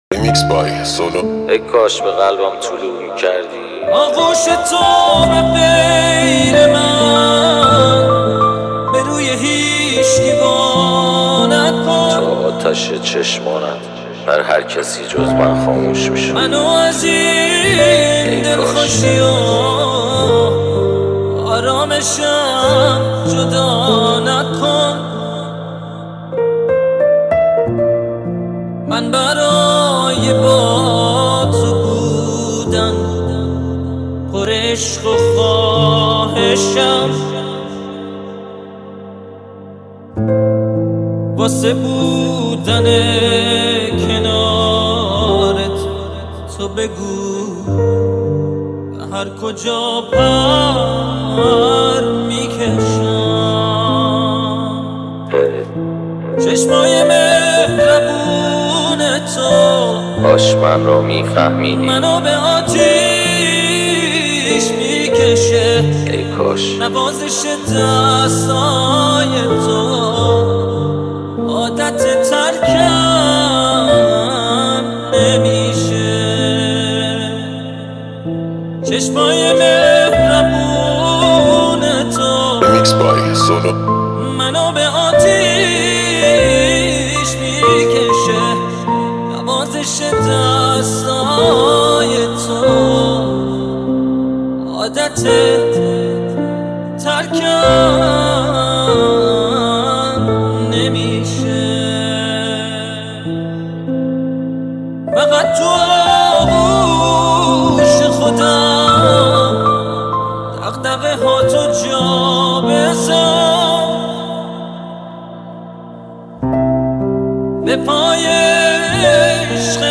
ژانر: پاپ/رپ